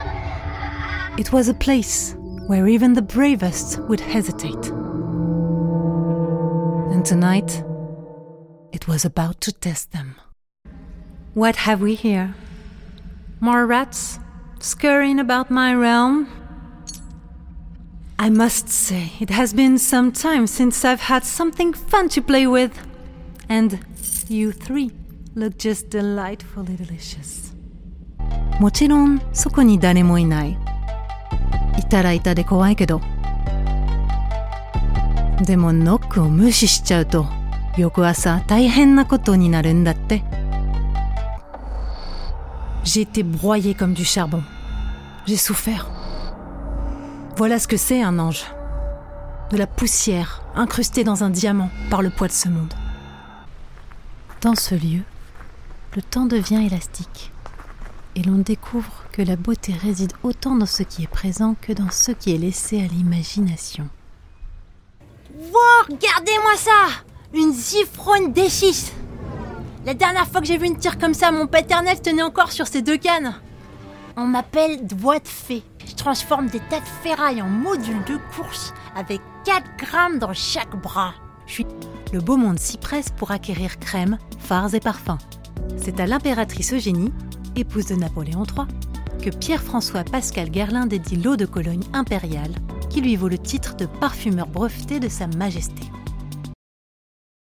Bande Démo multilingue Studio 2025
Voix off
12 - 45 ans - Mezzo-soprano